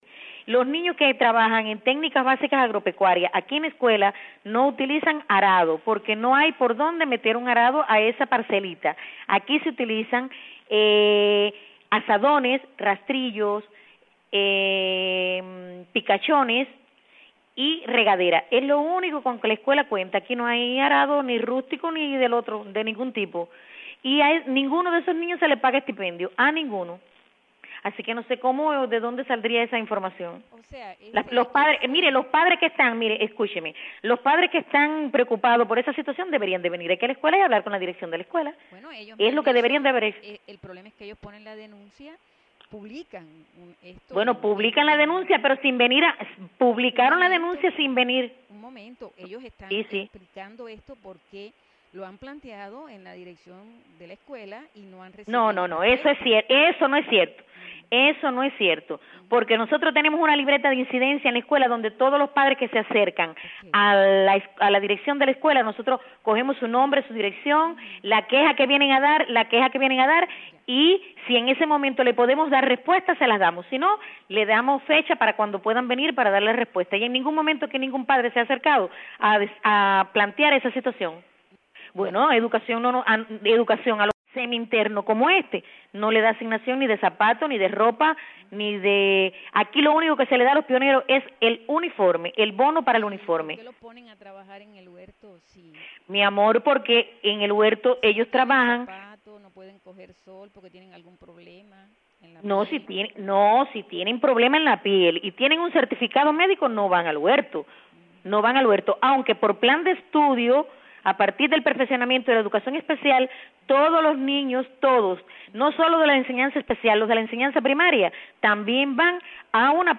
Declaraciones de la directora de escuela en Holguín